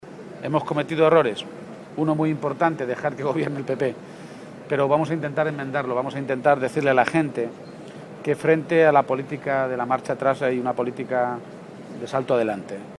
García-Page atiende a los medios de comunicación.
El secretario general del PSOE de Castilla-La Mancha, Emiliano García-Page, clausuró el Congreso provincial de los socialistas albaceteños
Garcia_Page-congreso_PSOE_AB-4.mp3